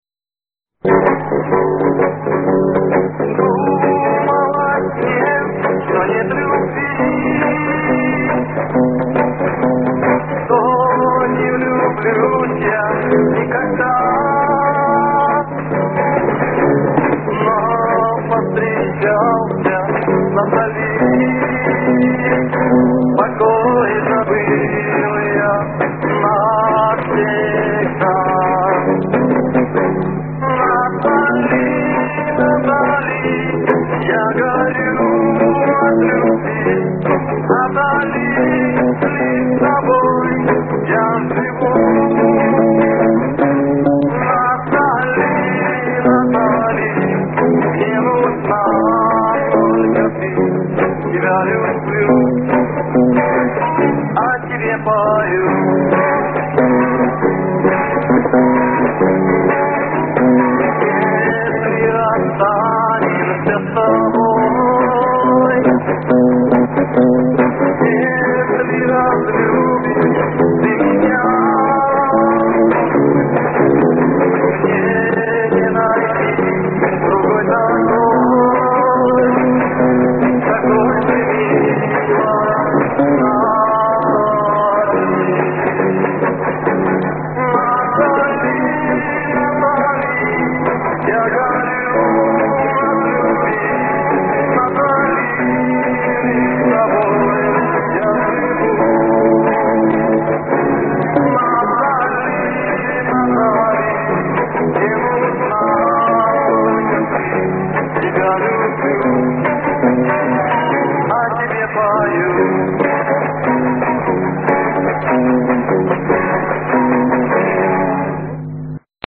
Лирическая песня
(дворовая)